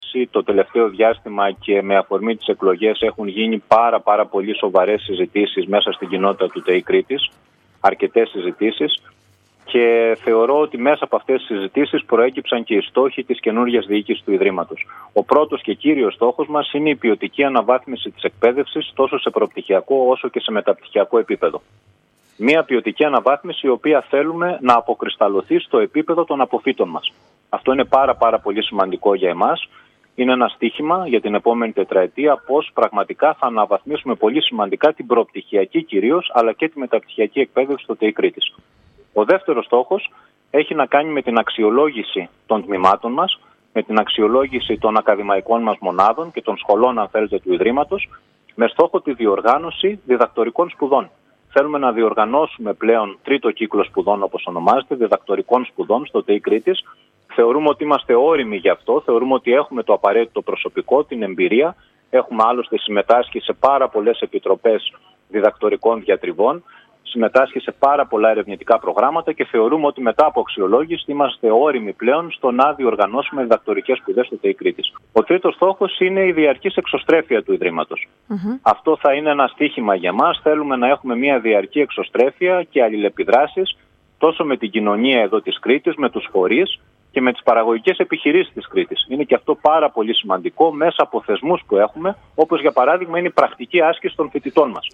Τους στόχους της νέας πρυτανείας του ΤΕΙ Κρήτης, μεταξύ των οποίων είναι η εξωστρέφεια, η διασύνδεση με την κοινωνία και η ανάπτυξη διδακτορικών προγραμμάτων σπουδών, περιέγραψε νωρίτερα σήμερα στην ΕΡΤ Ηρακλείου ο κ. Κατσαράκης.